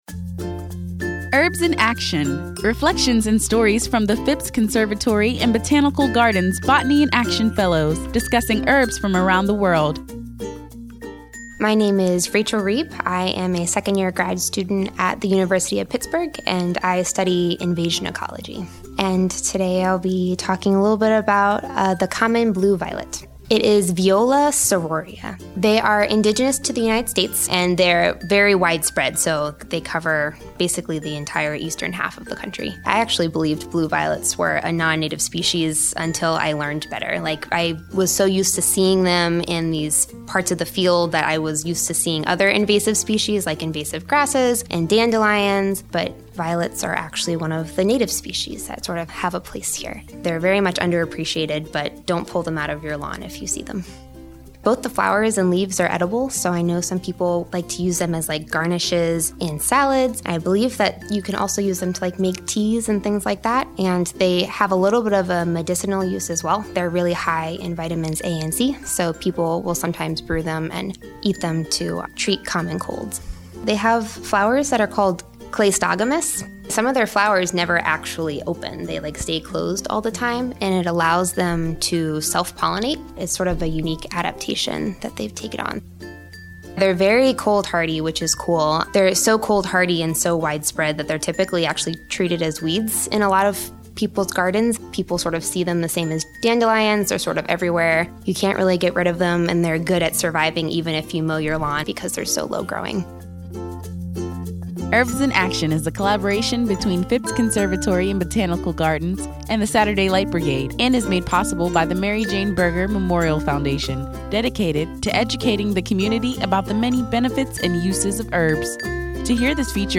Listen as they share their knowledge of and experiences with these herbs as botanists of the world.